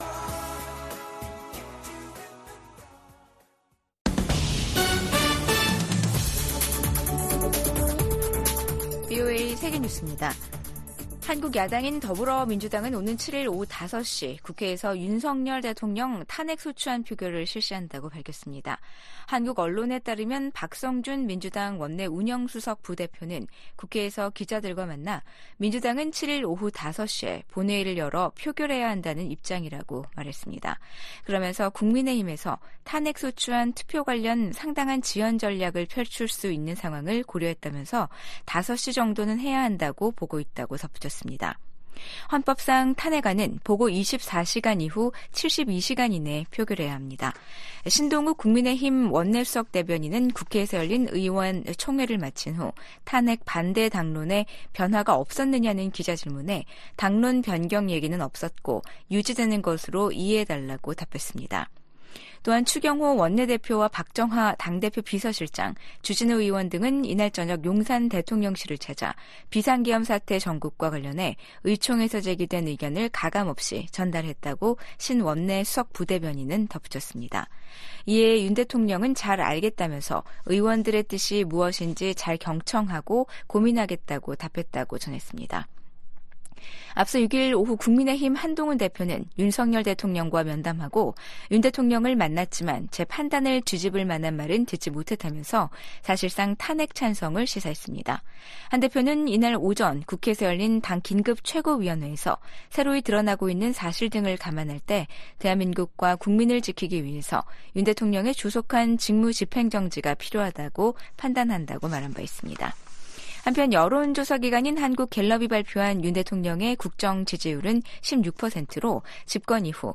VOA 한국어 아침 뉴스 프로그램 '워싱턴 뉴스 광장'입니다. 윤석열 대통령 탄핵소추안에 대한 국회 표결을 하루 앞두고 한국 내 정국은 최고조의 긴장으로 치닫고 있습니다. 미국 국무부는 한국이 대통령 탄핵 절차에 돌입한 것과 관련해 한국의 법치와 민주주의를 계속 지지할 것이라고 밝혔습니다. 한국의 계엄 사태와 관련해 주한미군 태세에는 변함이 없다고 미국 국방부가 강조했습니다.